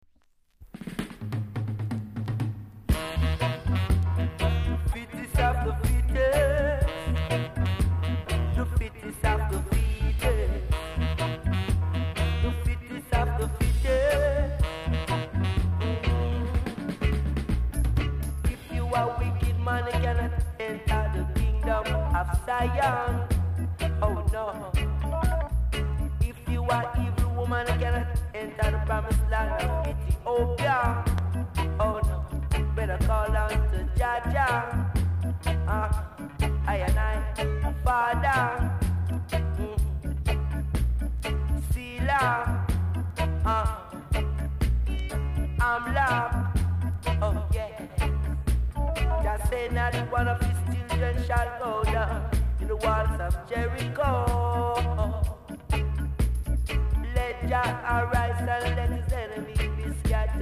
※多少小さなノイズはありますが概ね良好です。
コメント ROOTS CLASSIC!!